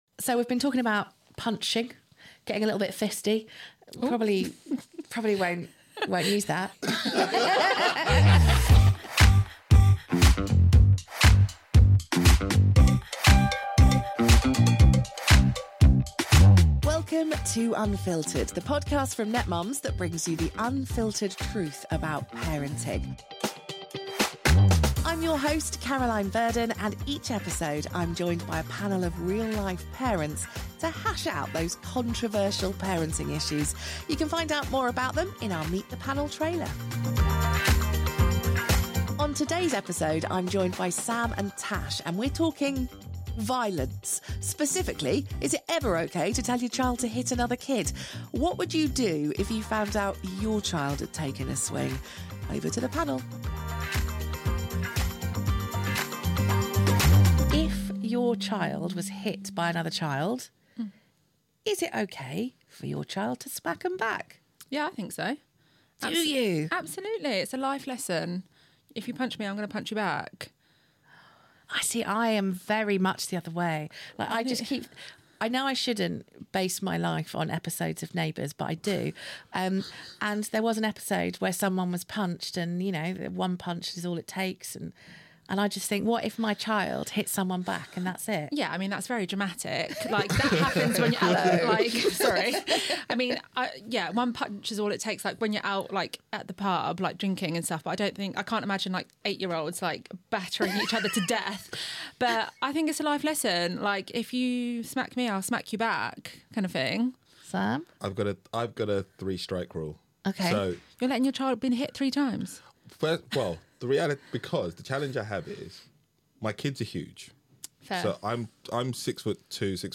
Join the panel for this controversial parenting debate where the gloves come off to discuss the age-old dilemma: to hit back or not to hit back.